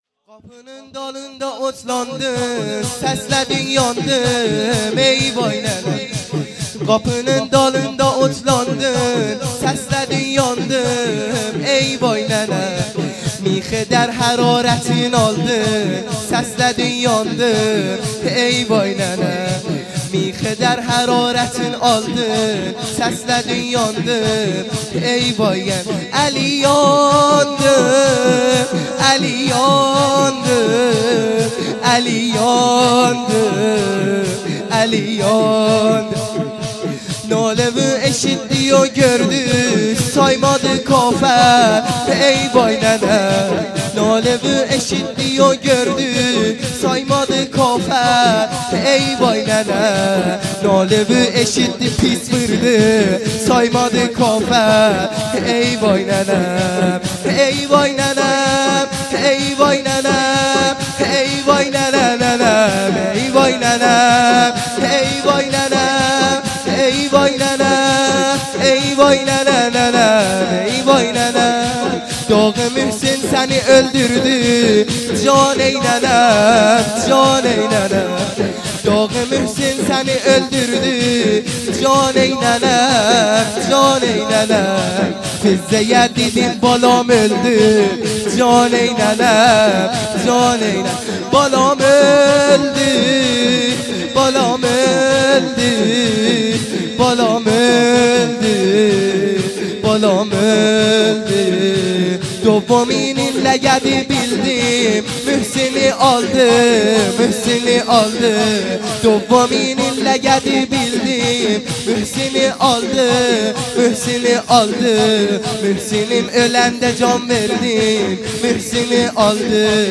مادرانه های خیمه گاهی